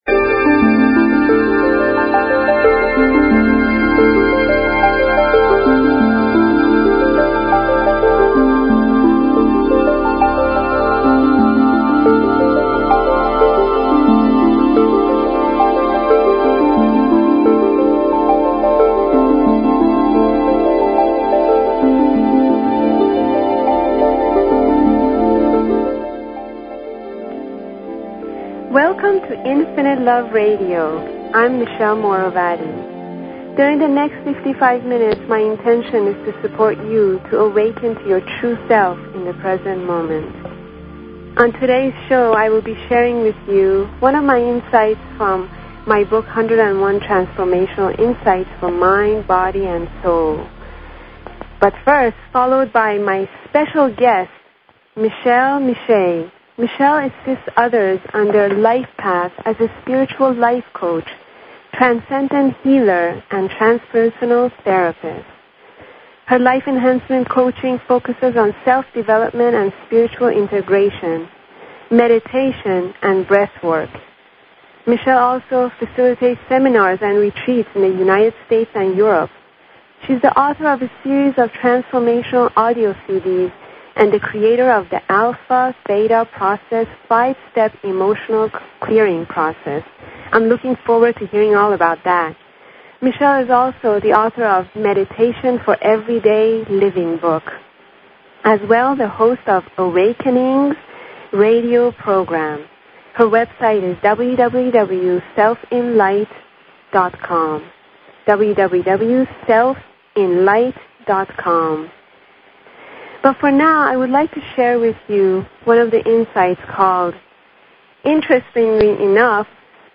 Talk Show Episode, Audio Podcast, Infinite_Love_Radio and Courtesy of BBS Radio on , show guests , about , categorized as